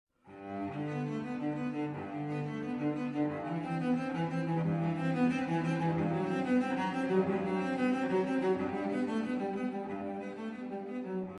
Audición de diferentes sonidos de la familia de cuerda frotada.
Violonchelo